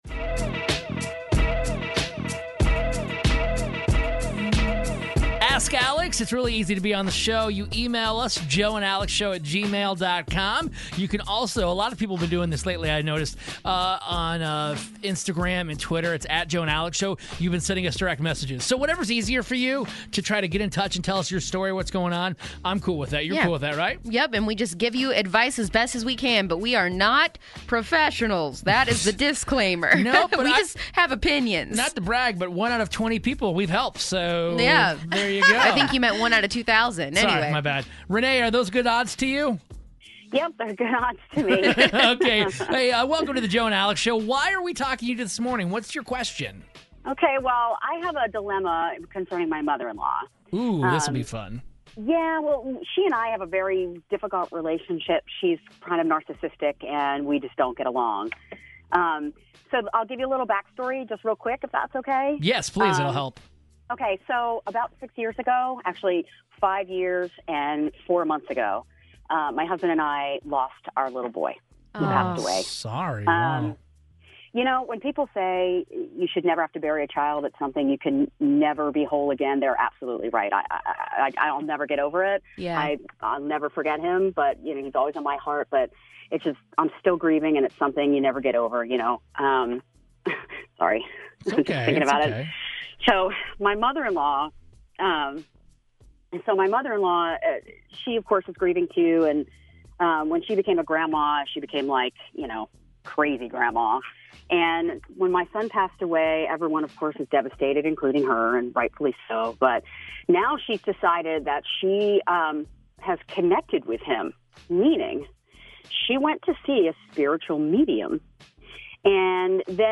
A psychic and a crazy mother-in-law is never a good mix, and this grieving woman is sharing what exactly they did to cross the line.